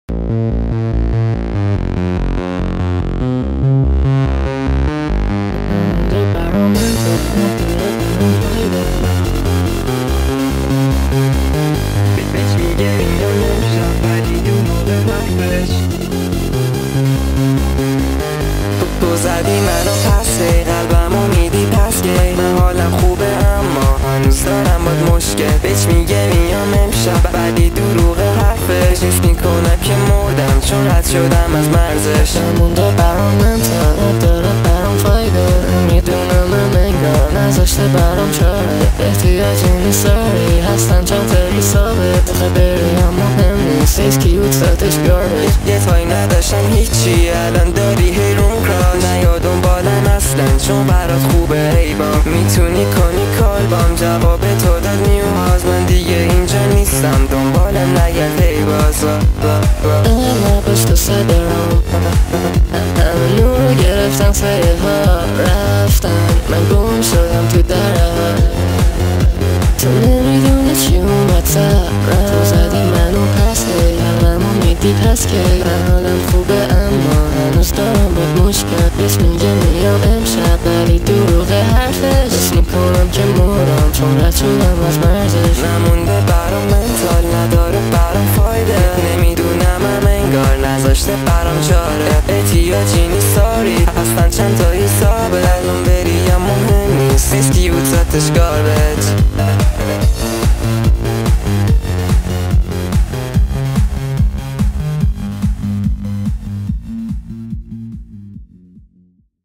شاد
فانک